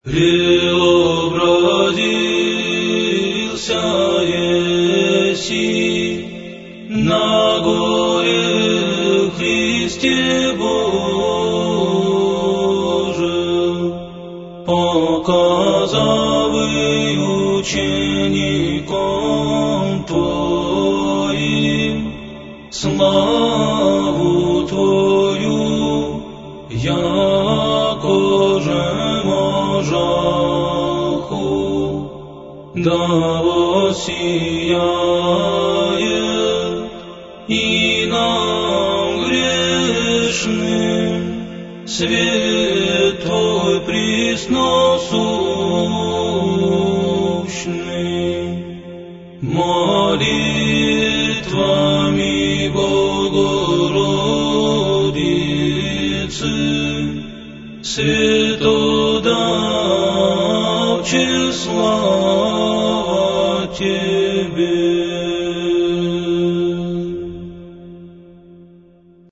Preobrazhenie_Gospodne.Tropar-f914a0.mp3